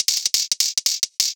Index of /musicradar/ultimate-hihat-samples/175bpm
UHH_ElectroHatB_175-02.wav